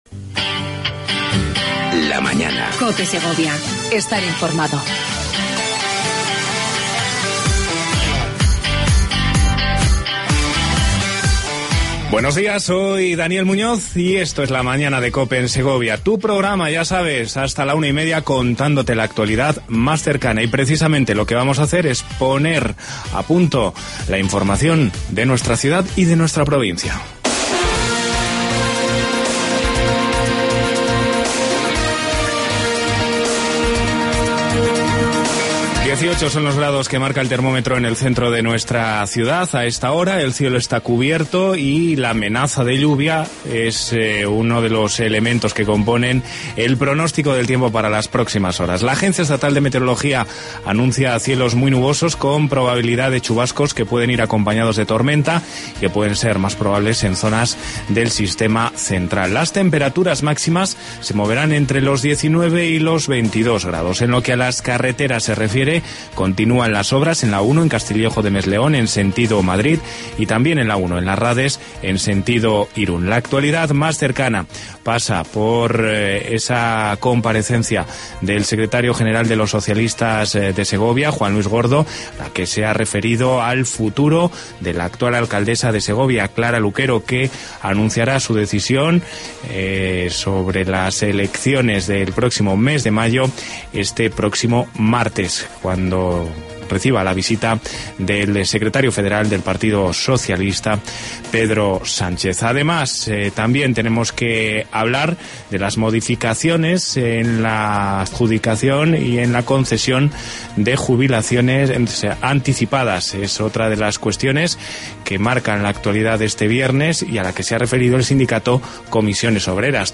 Entreista